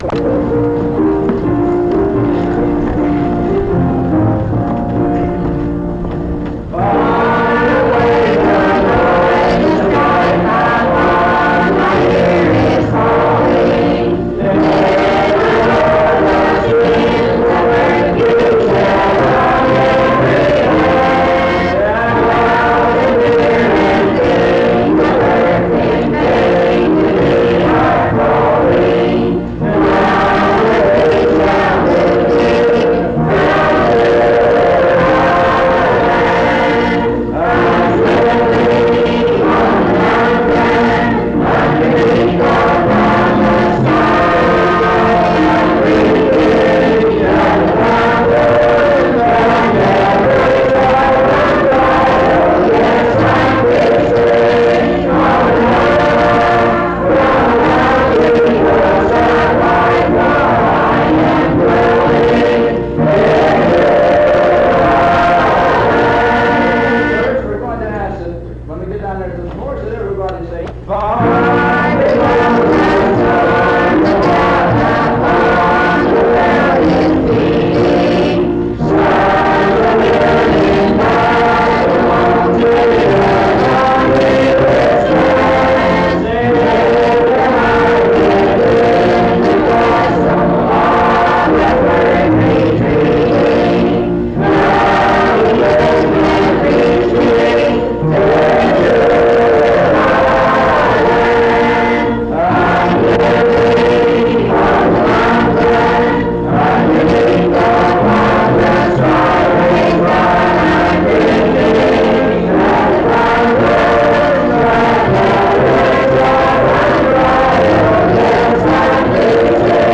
Dwelling In Beulah Land Item f0c69fa01ca0eae0017ea09c64cecddb4ffcfa91.mp3 Title Dwelling In Beulah Land Creator Congregation Description This recording is from the Monongalia Tri-District Sing. Highland Park Methodist Church, Morgantown, Monongalia County, WV.